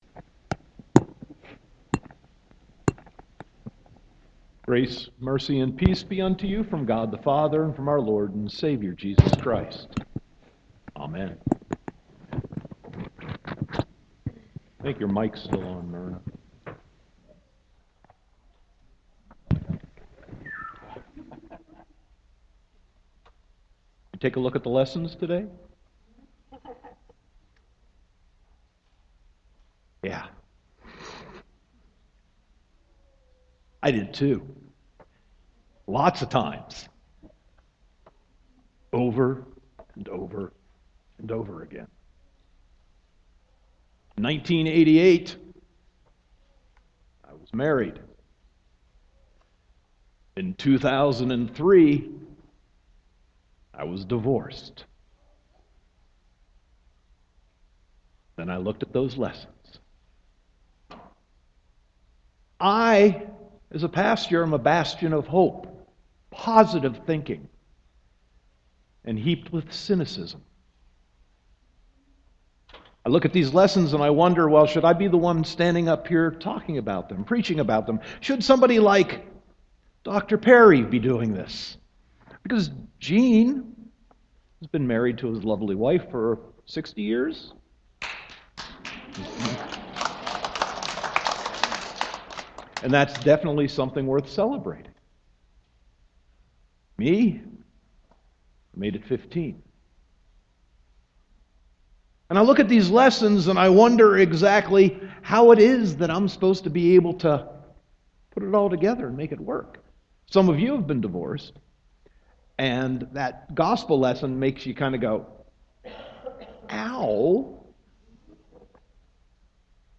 Sermon 10.4.2015